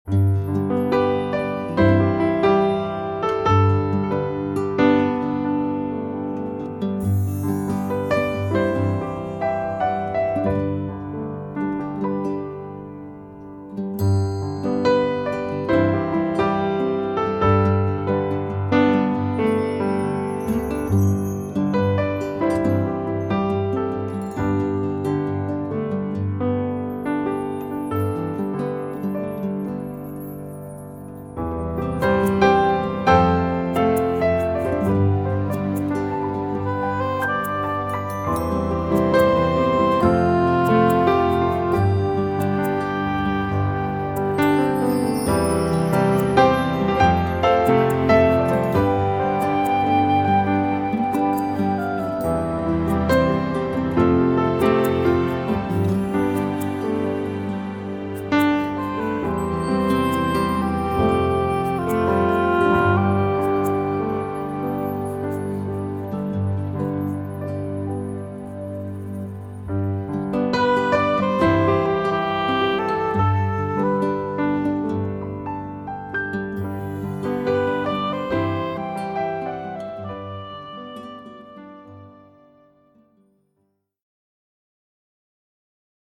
ヒーリングＣＤ
優しいピアノの音がリラックス効果を高めます。